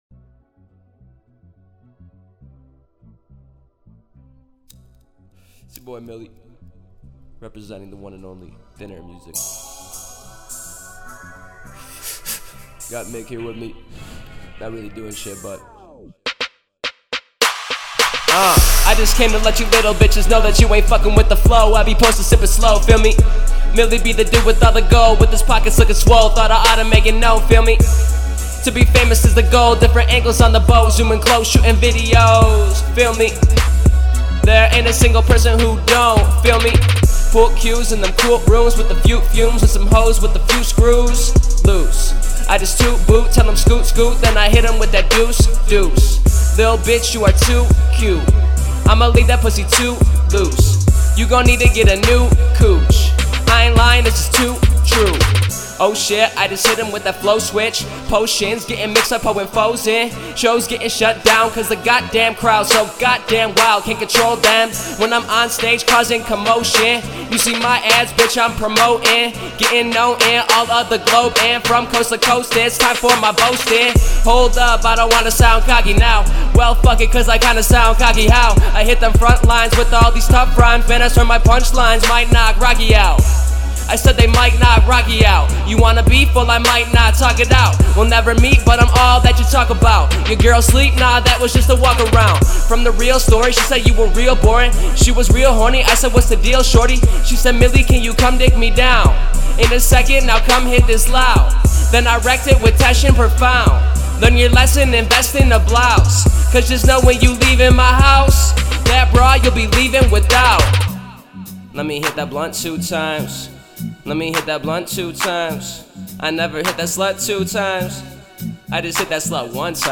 Hiphop
remix